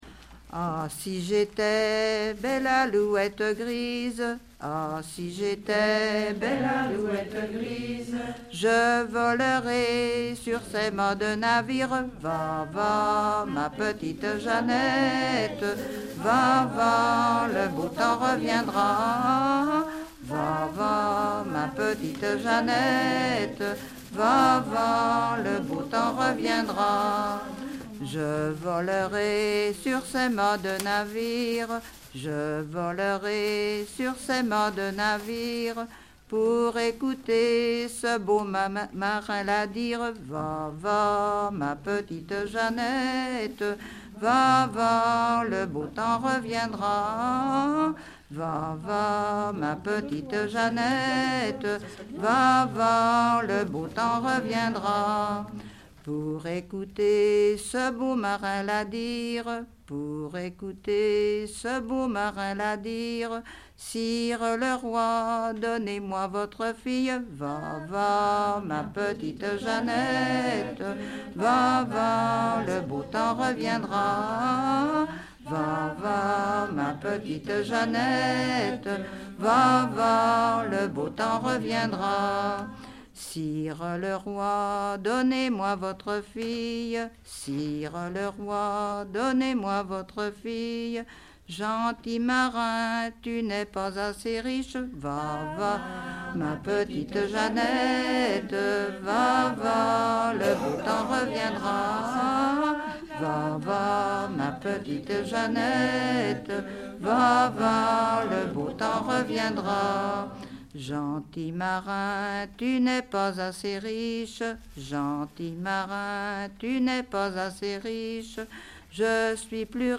Localisation Port-Saint-Père
Genre laisse
Pièce musicale inédite